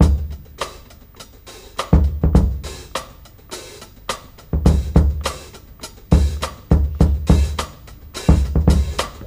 • 104 Bpm Drum Loop Sample D Key.wav
Free breakbeat sample - kick tuned to the D note. Loudest frequency: 562Hz
104-bpm-drum-loop-sample-d-key-lVB.wav